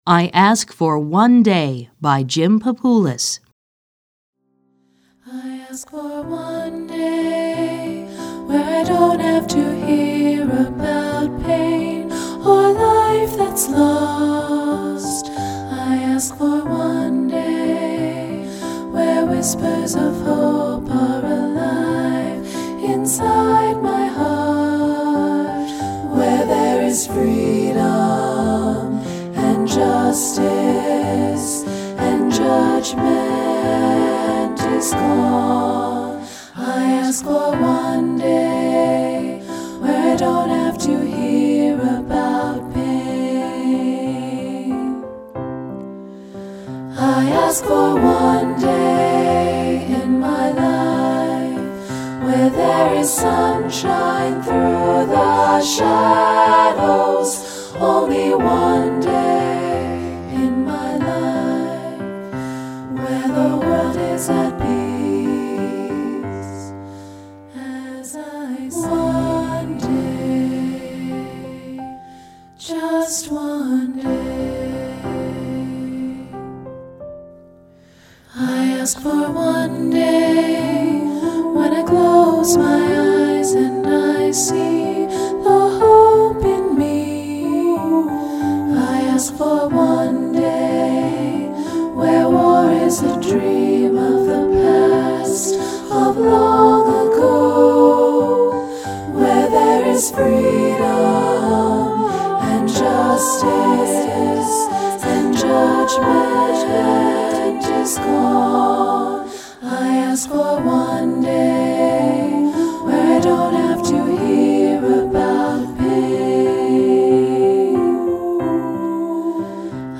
Choral Concert/General Graduation/Inspirational
SAB Divisi